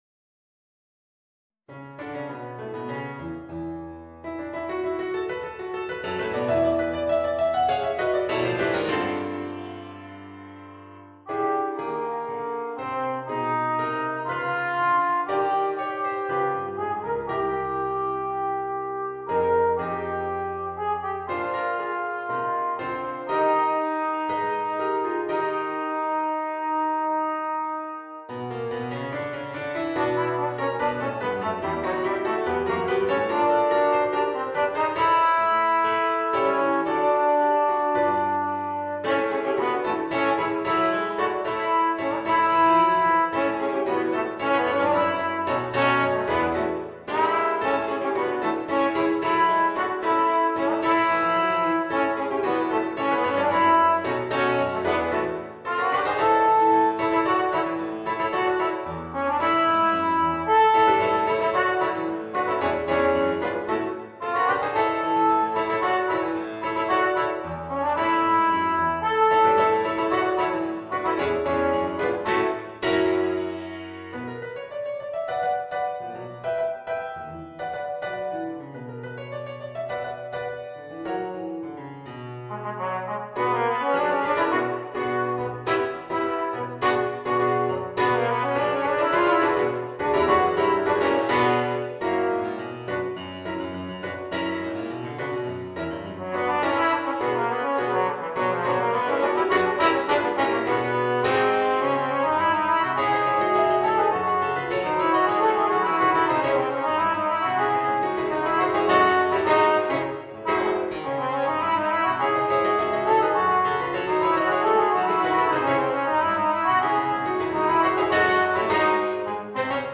Voicing: Alto Trombone and Piano